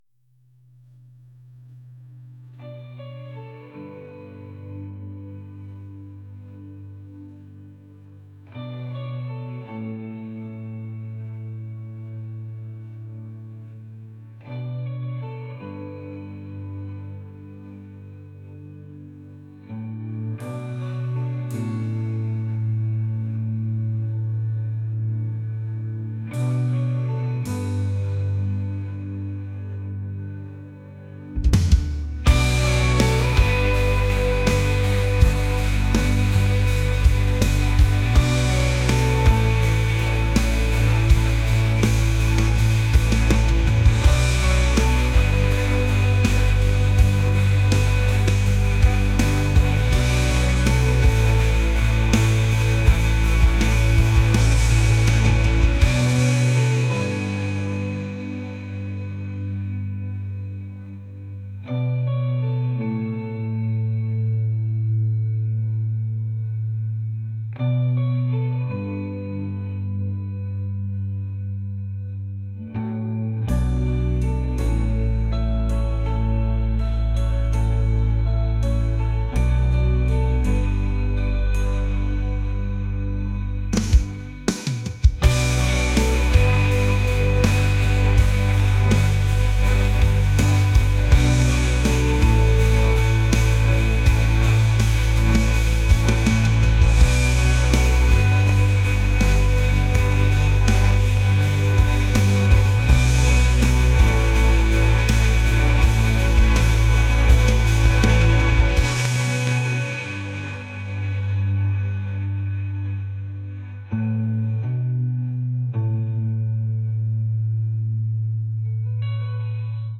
pop | ambient | indie